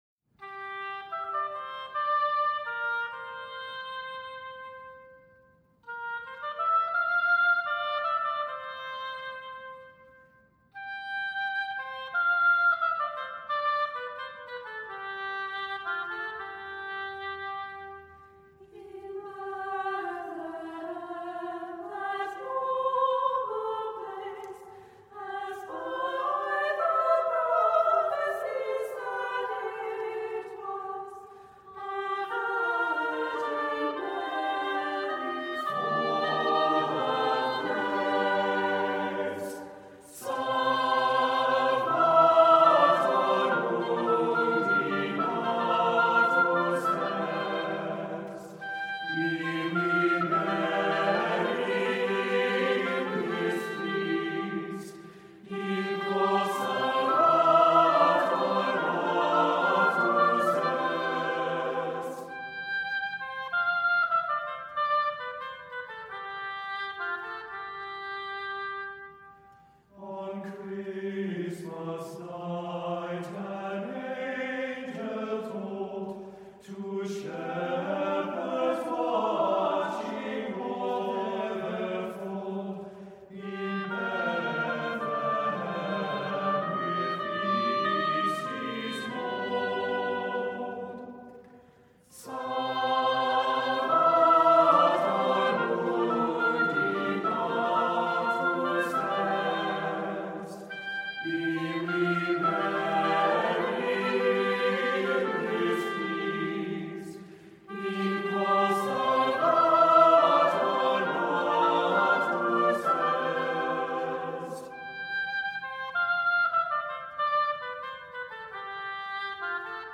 Voicing: SATB and Oboe